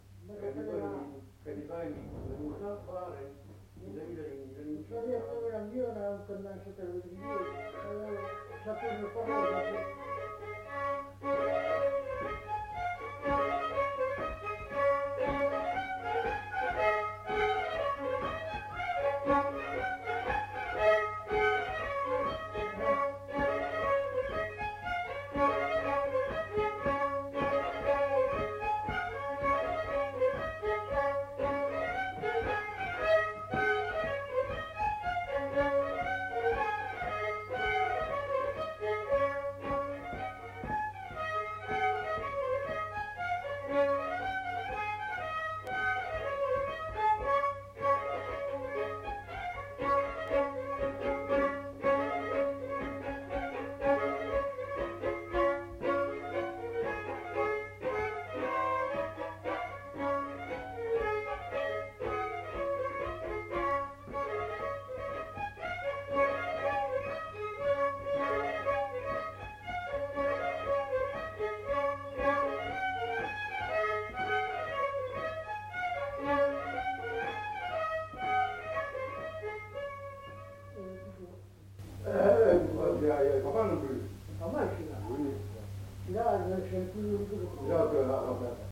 Aire culturelle : Lomagne
Genre : morceau instrumental
Instrument de musique : violon
Danse : rondeau
Notes consultables : L'informateur fredonne l'air en début d'item. Deux violons.